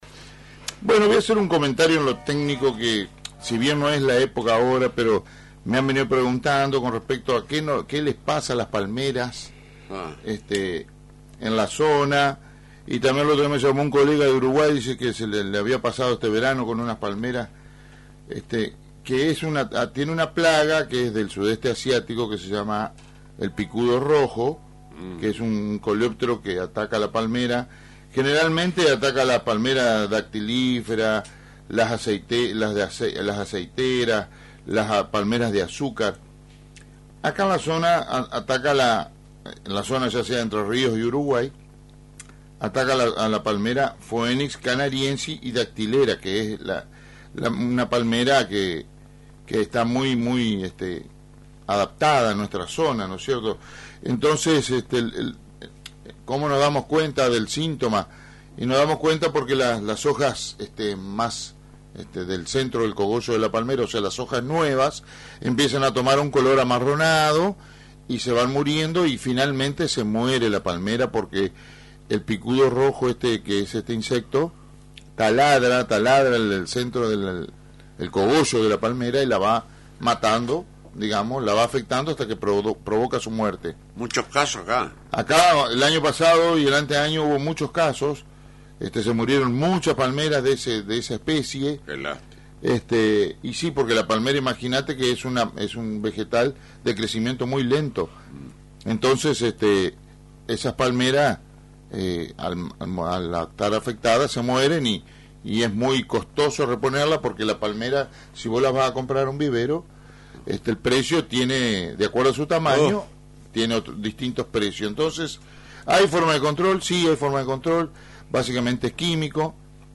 ¿Qué temas aborda la charla de ésta semana?